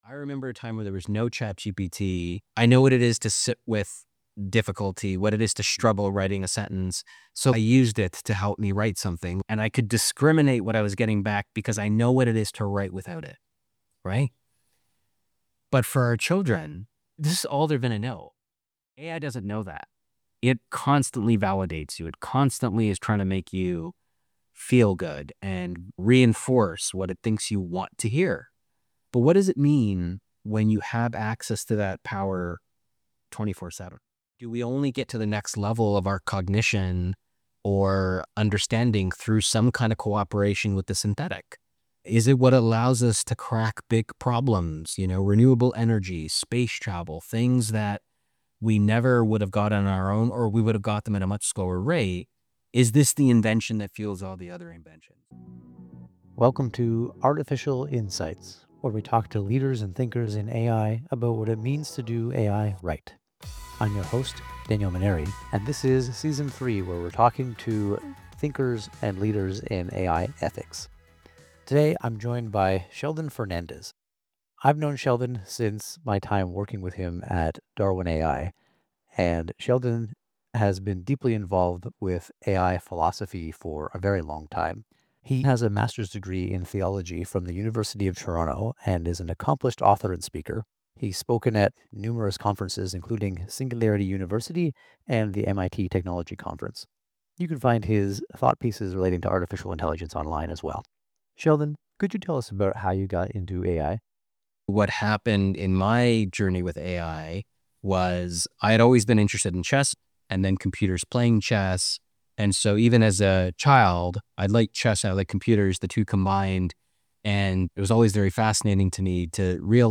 Play Rate Listened List Bookmark Get this podcast via API From The Podcast 1 Candid conversations and real-world stories about building AI into products and businesses.